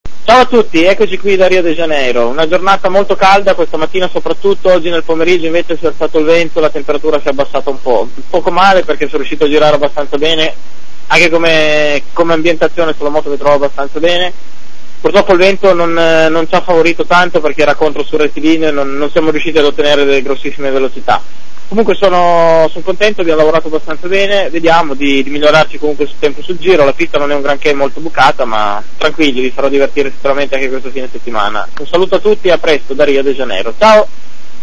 ascolta dal vivo Roberto Rolfo